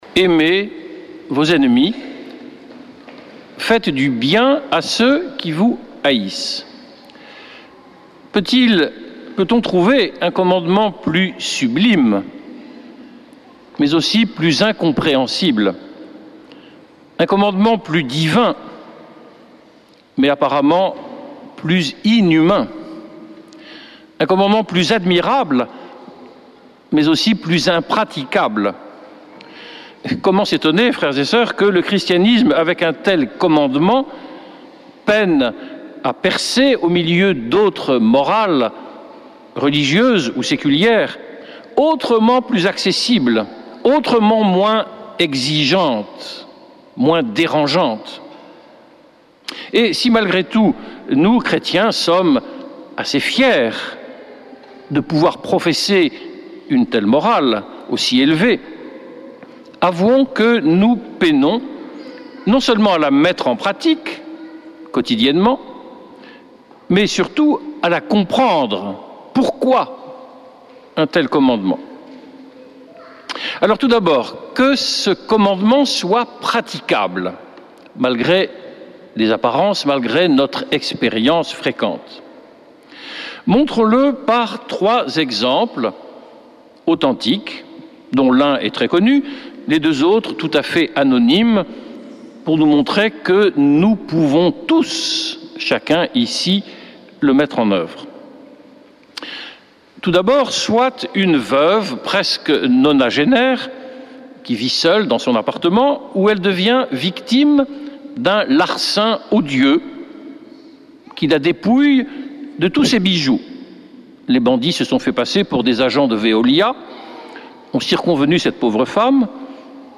Accueil \ Emissions \ Foi \ Prière et Célébration \ Messe depuis le couvent des Dominicains de Toulouse \ Aimez vos ennemis !